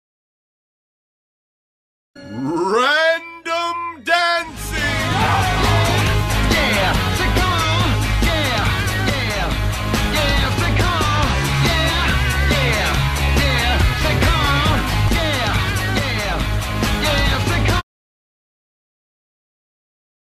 Dancing Sound Effects MP3 Download Free - Quick Sounds